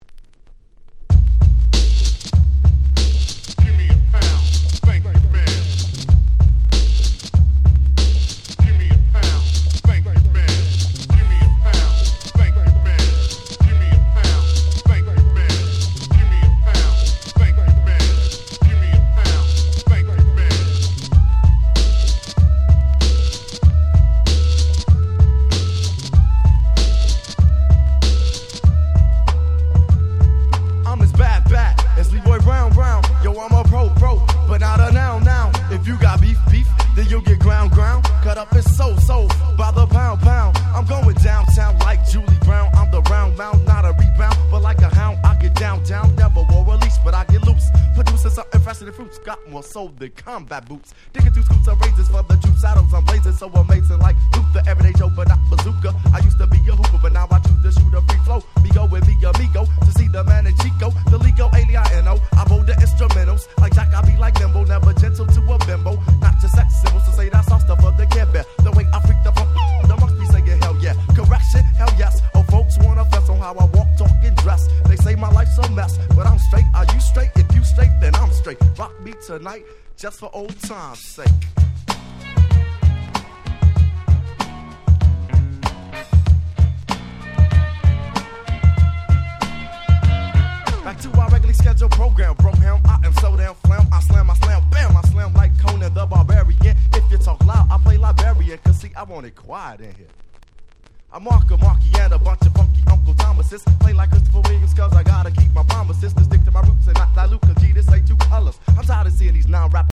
92' Hip Hop Super Classics !!
90's Boom Bap ブーンバップ